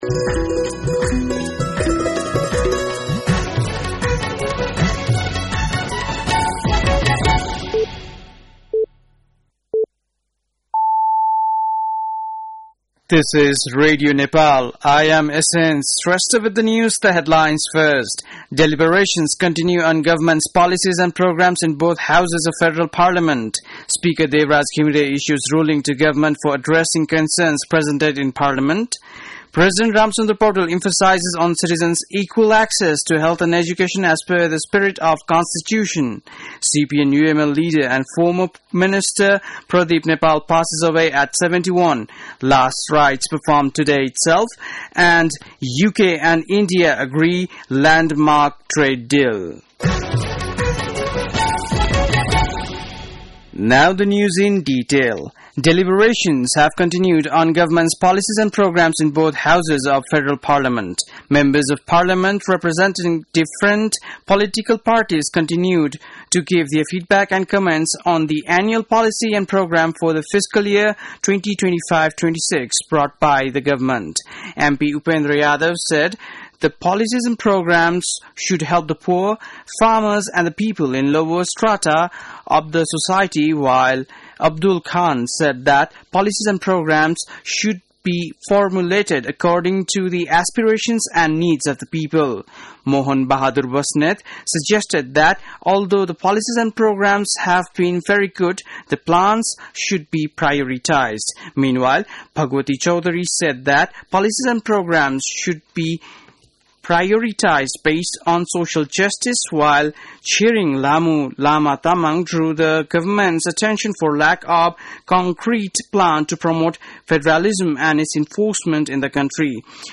बेलुकी ८ बजेको अङ्ग्रेजी समाचार : २३ वैशाख , २०८२